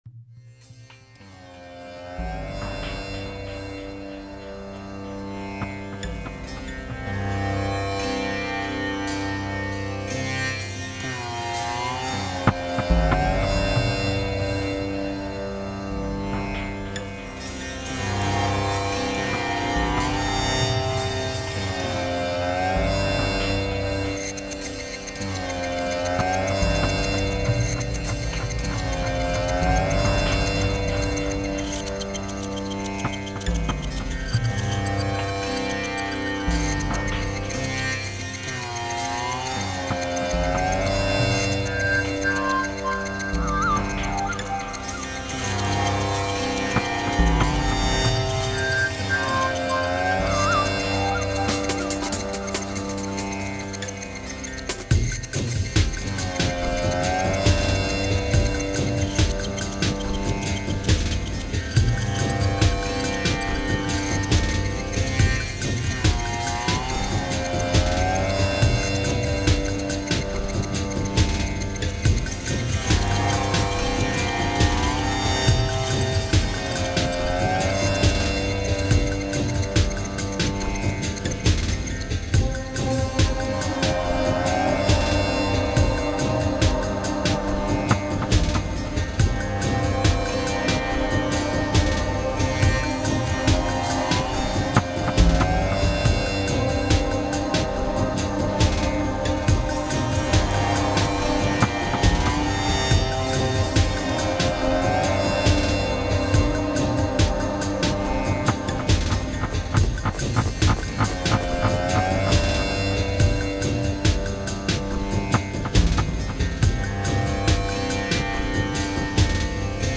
Genre: Dub, Downtempo, Ambient.